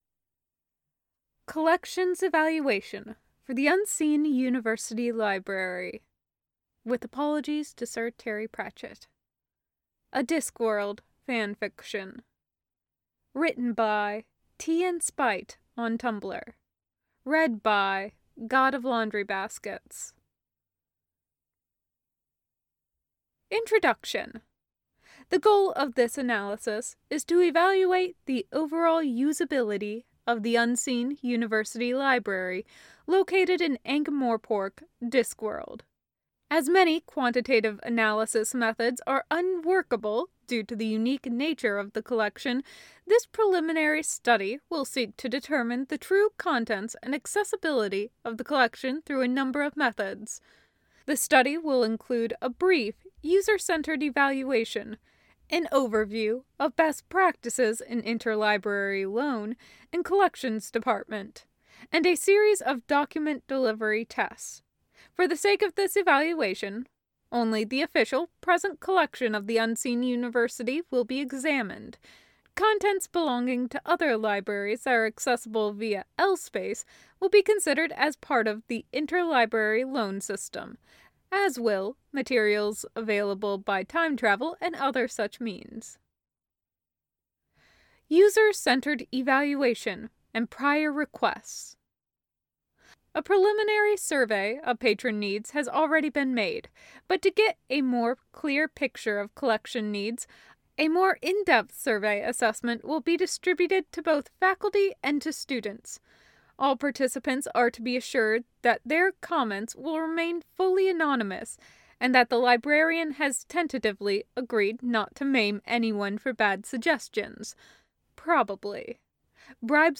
[Podfic] Collection Evaluation for The Unseen University Library